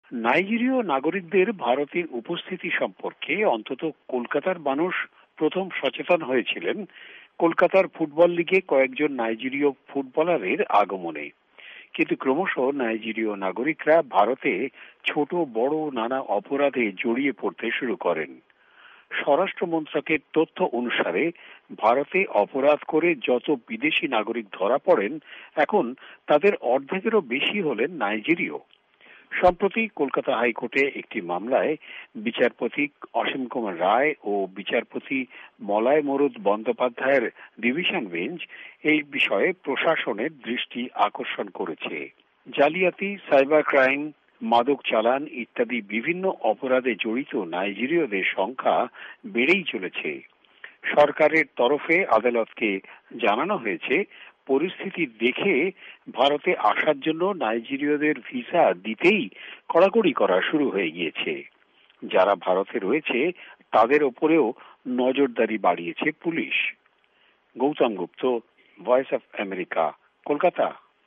কলকাতা থেকে জানিয়েছেন